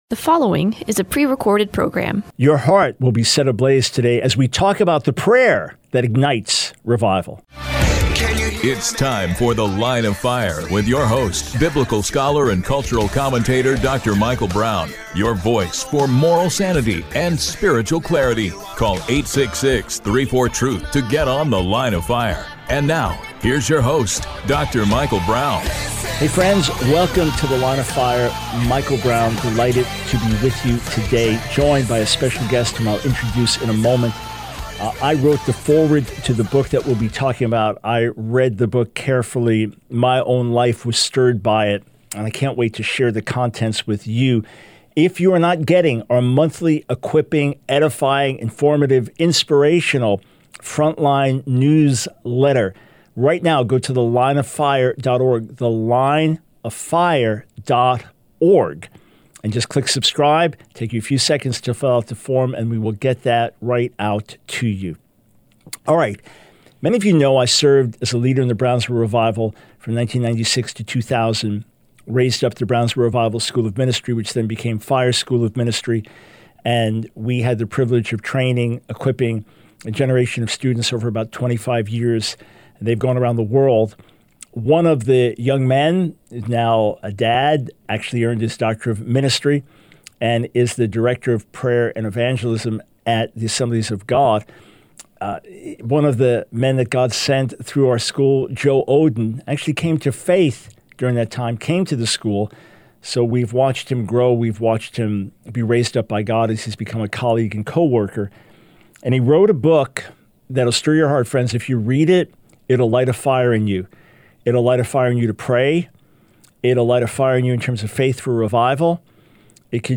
The Line of Fire Radio Broadcast for 06/03/24.